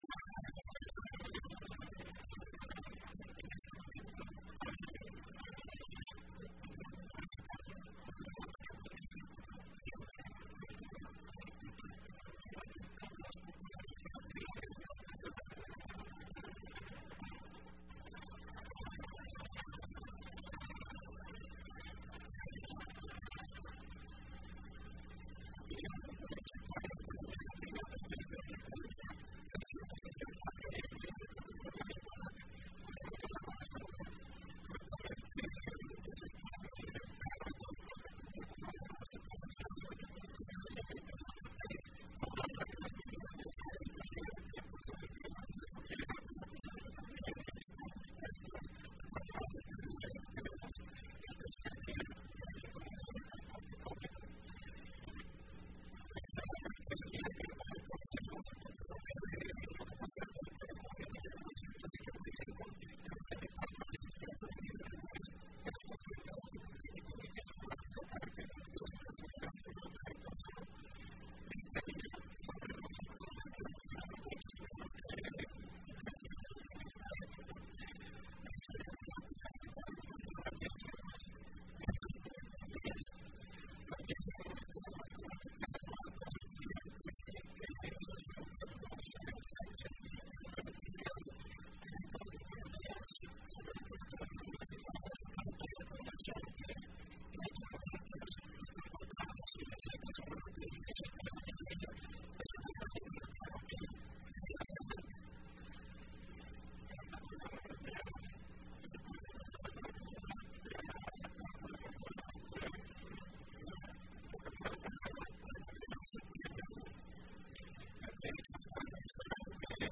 Entrevista Opinión Universitaria(29 de julio 2015):Masacre del 30 de Julio de 1975